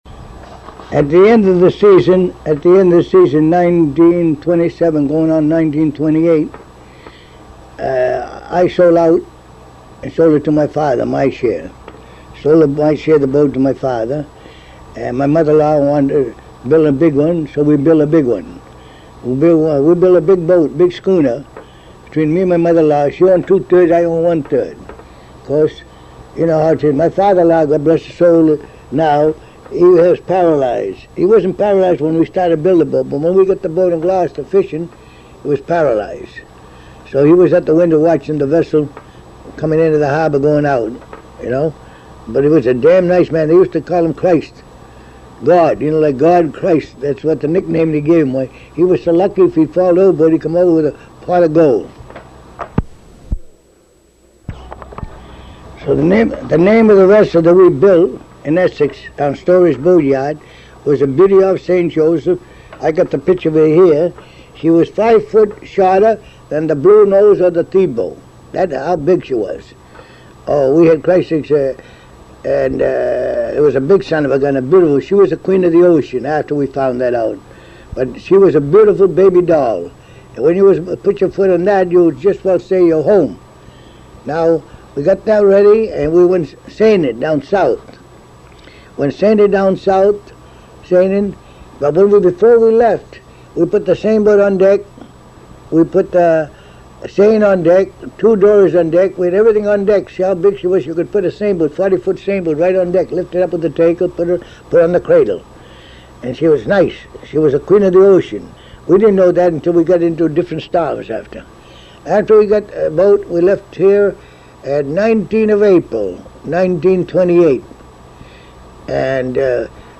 At the time he was having trouble with circulation in his legs and spent most of the time at home so he bought a tape recorder. He had trouble with the tape recorder and threw all of the tapes away.